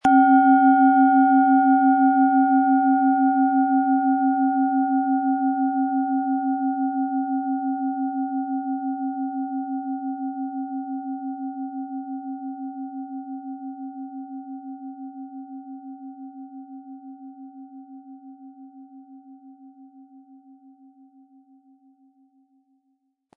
Nach Jahrhunderte alter Tradition von Hand getriebene Planetenklangschale Biorhythmus Körper.
• Mittlerer Ton: Mond
Im Sound-Player - Jetzt reinhören können Sie den Original-Ton genau dieser Schale anhören.
Harmonische Töne erhalten Sie, wenn Sie die Schale mit dem kostenfrei beigelegten Klöppel ganz sanft anspielen.
PlanetentöneBiorythmus Körper & Mond
SchalenformOrissa
MaterialBronze